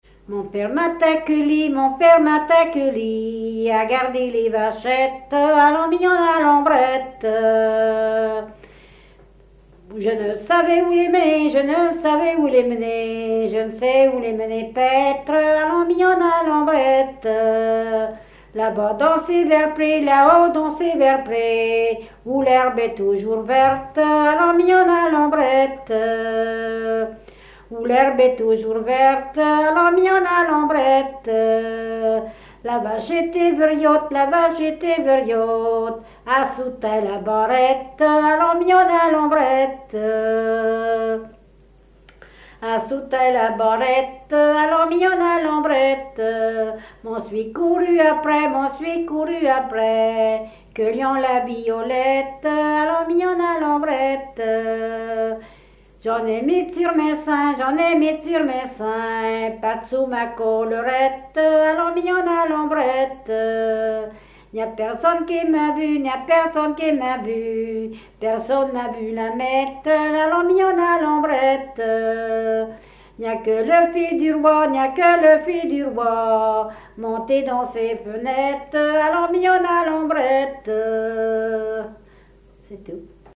Localisation Barbâtre (Plus d'informations sur Wikipedia)
Fonction d'après l'analyste danse : ronde ;
Genre strophique
Catégorie Pièce musicale inédite